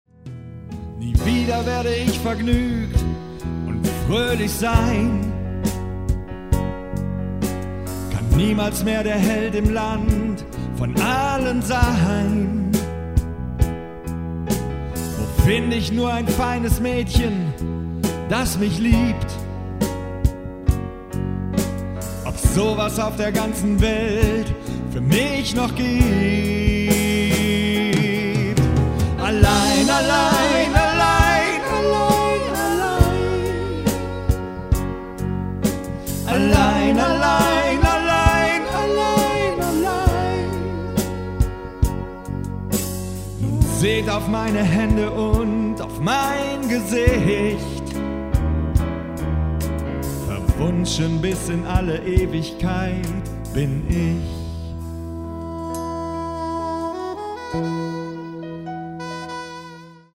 Singetrack